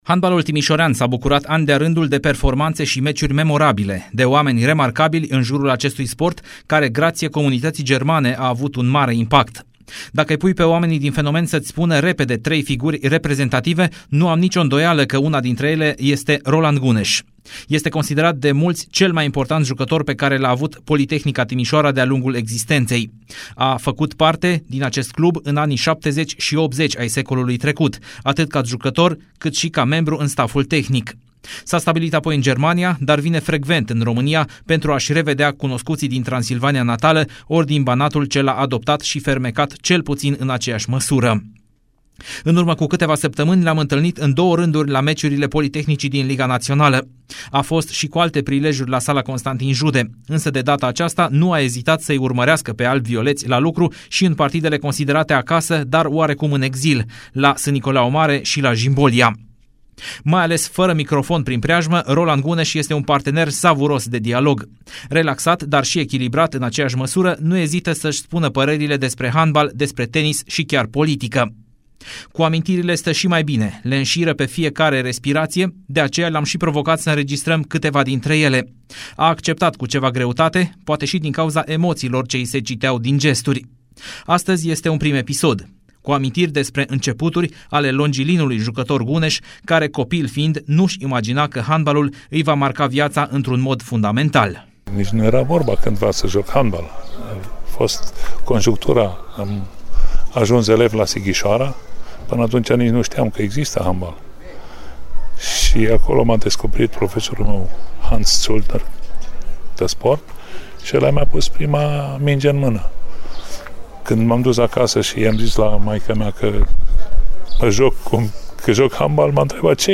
Pe care o povestește în stilul său inconfundabil, presărat cu mult umor.